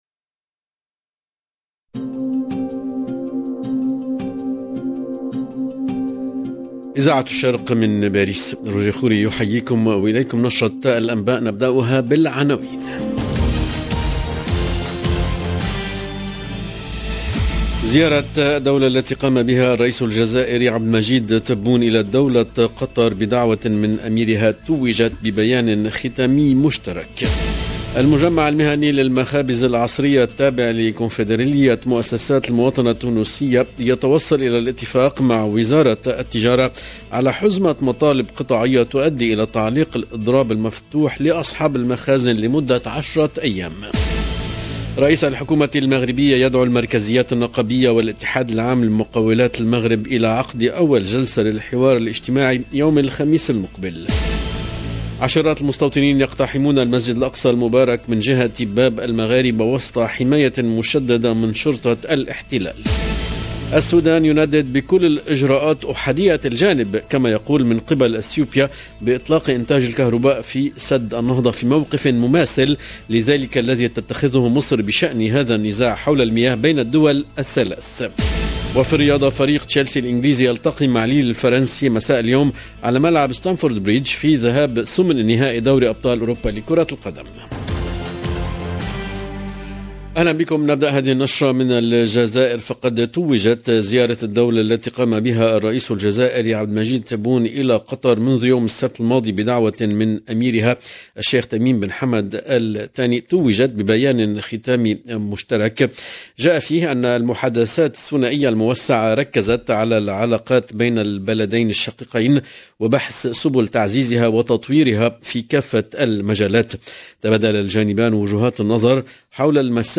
LE JOURNAL DE MIDI 30 EN LANGUE ARABE DU 22/02/22